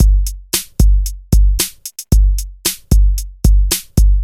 • 113 Bpm Breakbeat G Key.wav
Free drum loop sample - kick tuned to the G note.
113-bpm-breakbeat-g-key-aJo.wav